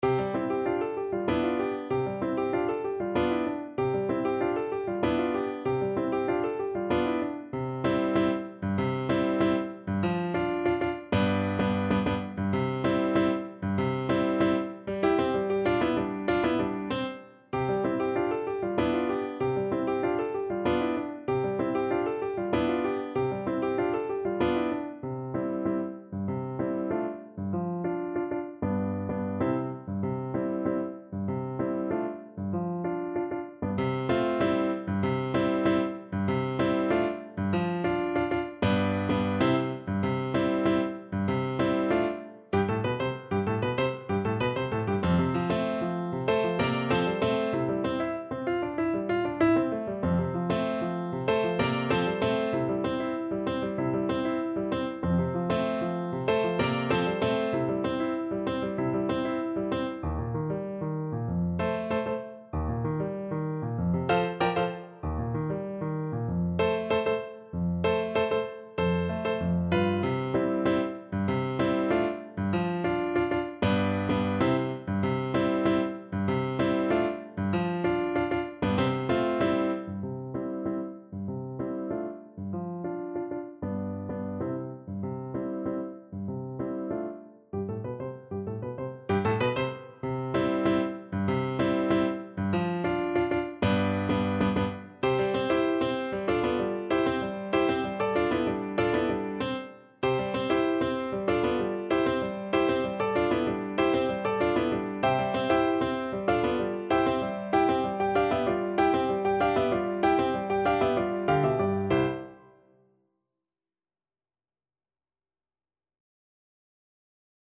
C major (Sounding Pitch) (View more C major Music for Oboe )
4/4 (View more 4/4 Music)
Classical (View more Classical Oboe Music)